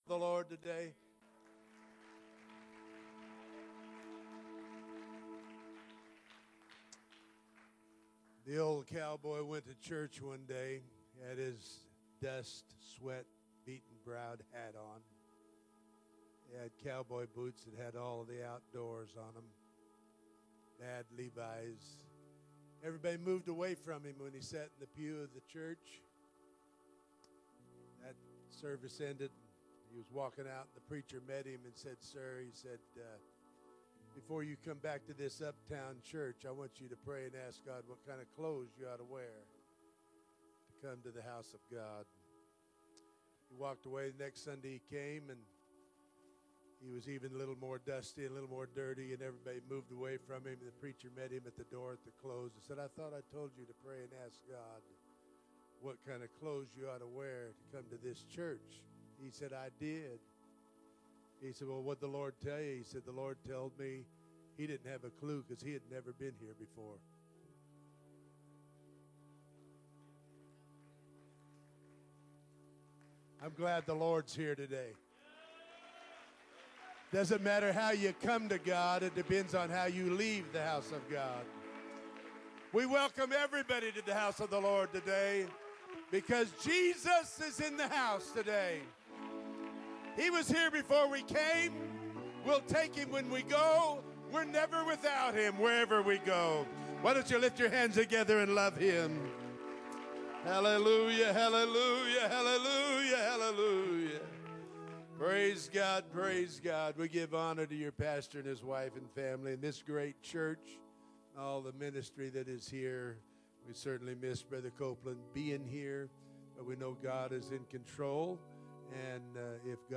First Pentecostal Church Preaching 2017